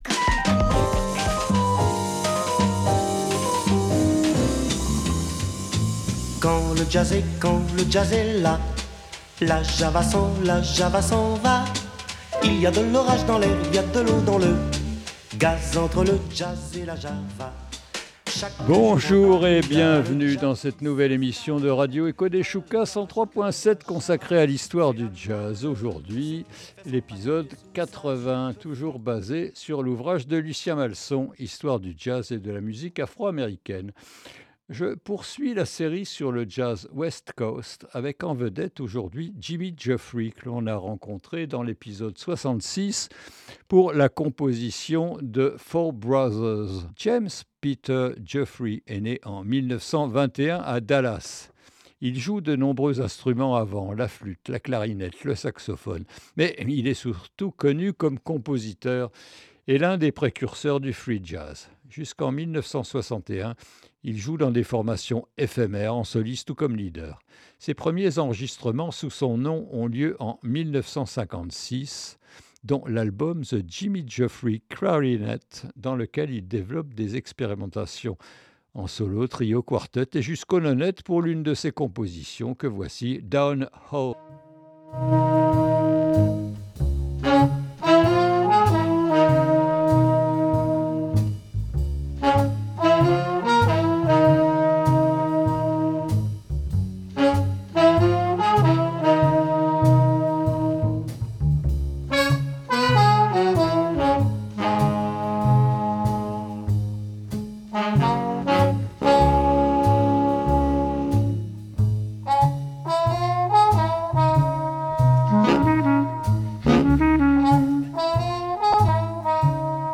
Quand le jazz est là est une nouvelle émission consacrée à l’histoire du jazz.